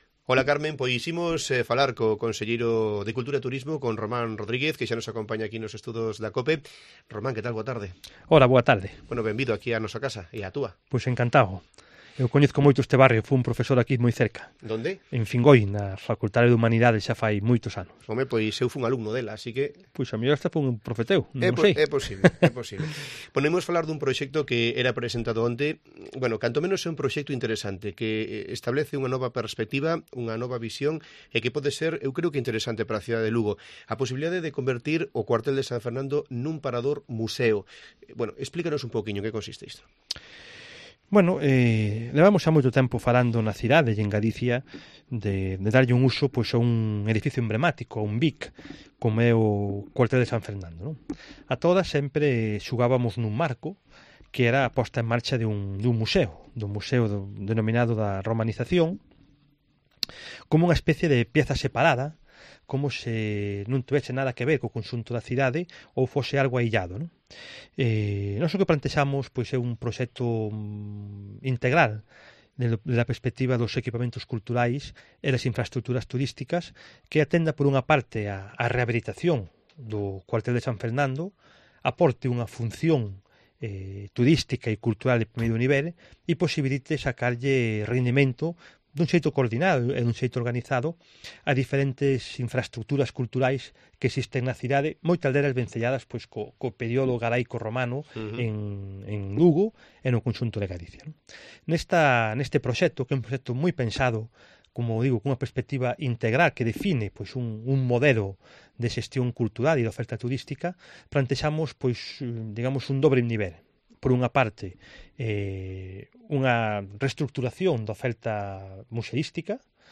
Escucha la entrevista completa con el conselleiro de Cultura e Turismo, Román Rodríguez